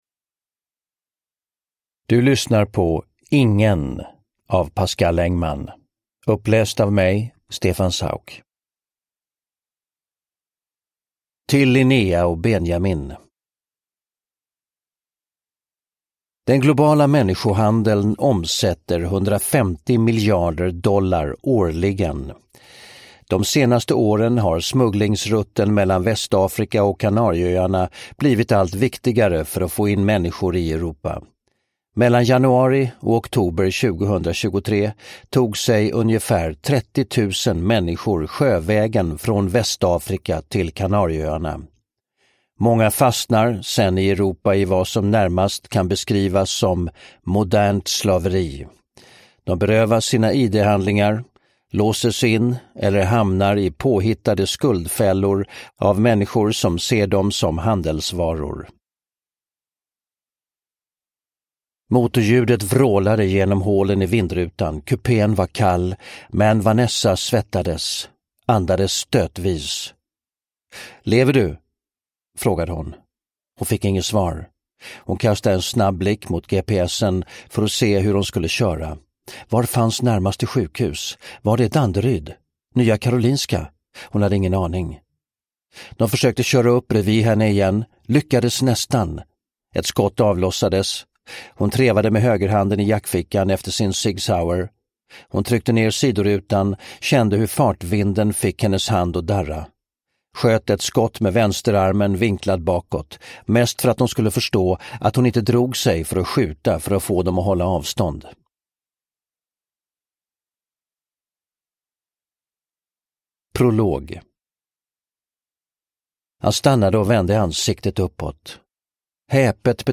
Uppläsare: Stefan Sauk
Ljudbok